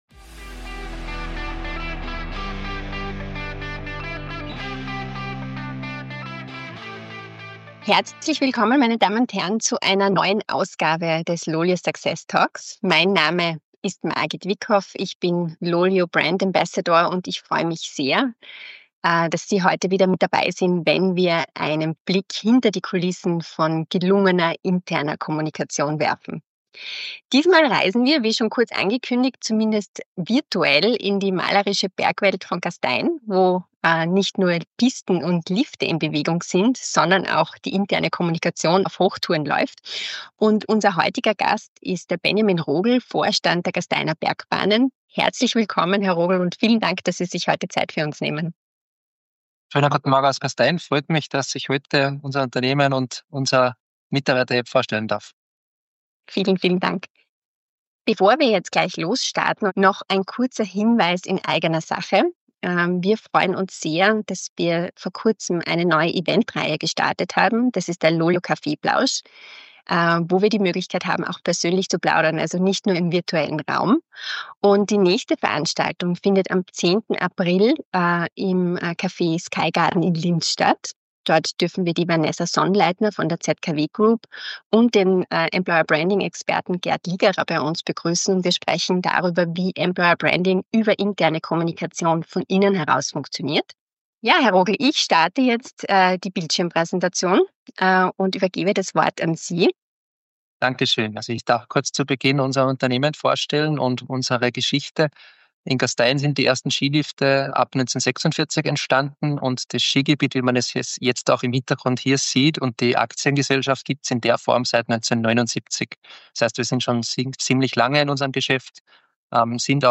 Success Talks by LOLYO ist ein Podcast mit ausgewählten Talkgästen und spannenden Themen rund um die unternehmensinterne Kommunikation und Mitarbeiter-Apps.